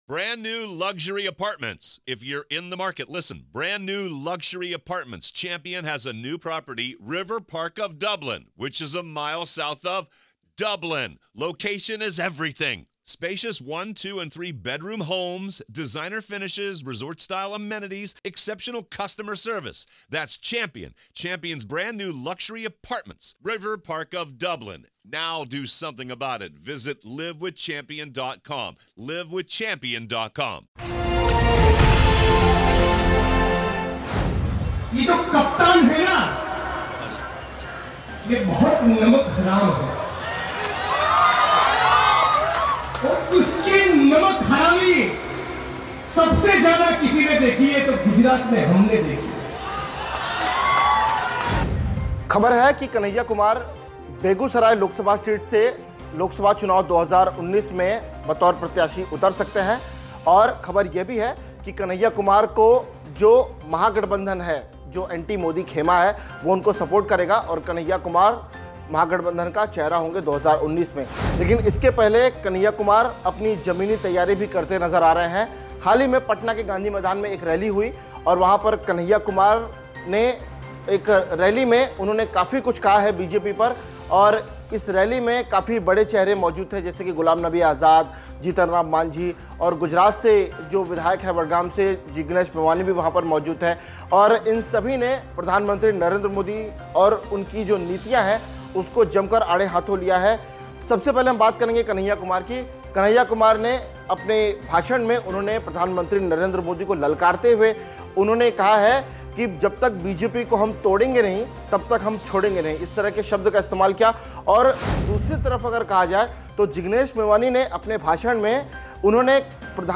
गुजरात से विधायक जिग्नेश मेवानी ने गुरुवार को पटना के गांधी मैदान में प्रधानमंत्री नरेंद्र मोदी के लिए विवादित शब्दों का इस्तेमाल किया। भारतीय कम्युनिस्ट पार्टी (भाकपा) 'भाजपा हराओ, देश बचाओ' रैली को संबोधित करते हुए जिग्नेश मेवानी ने 9 मिनट के भाषण में 6 बार प्रधानमंत्री नरेंद्र मोदी 'नमक हराम' कहकर संबोधित किया।